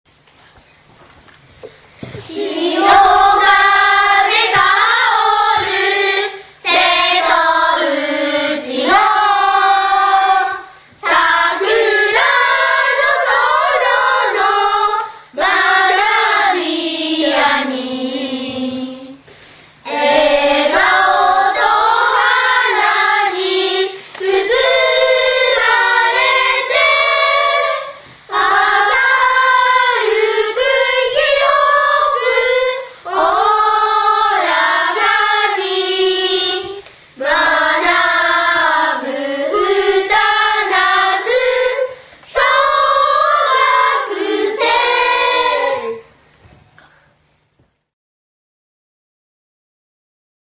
（平成１２年１１月に３・４年生によって収録したMP3ファイルで約800kバイトあります。）